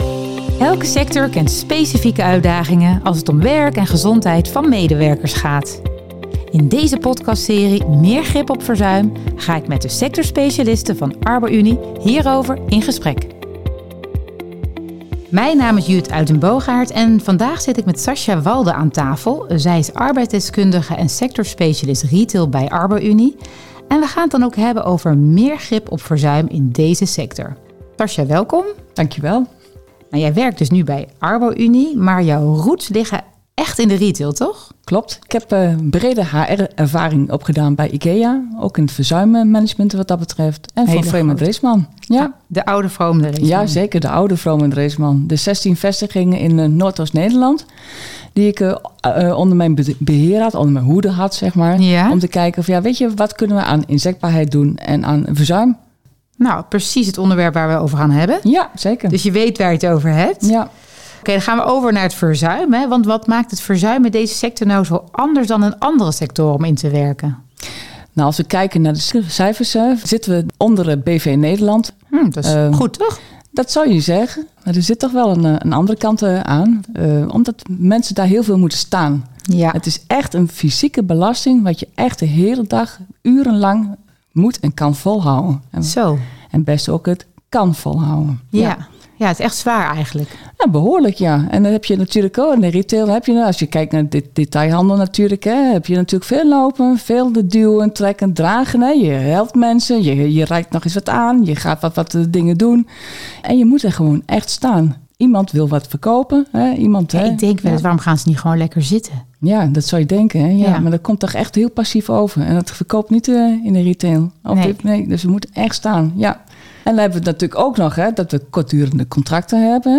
Elke sector kent specifieke uitdagingen als het om werk en gezondheid van medewerkers gaat. In deze podcastserie "Meer grip op verzuim" gaan we in gesprek met verschillende sectorspecialisten over de aanpak van verzuim in hun sector.